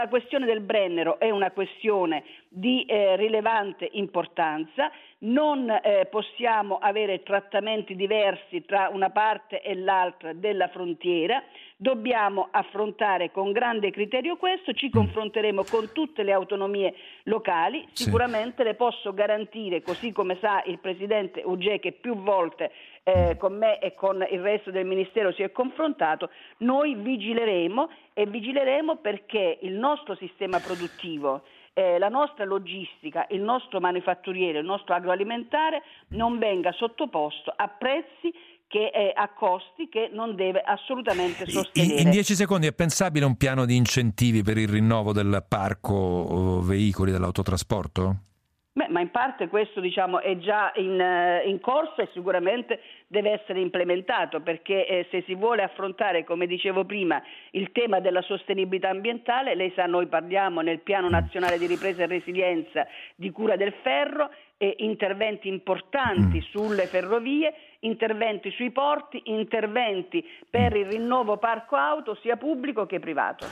Quirinale – Bellanova, viceministra Infrastrutture e mobilità sostenibili, a 24 Mattino su Radio 24: Berlusconi metta fine al mercato indecente, ora nome comune
Così Teresa Bellanova, viceministra Infrastrutture e mobilità sostenibili, a 24 Mattino su Radio 24.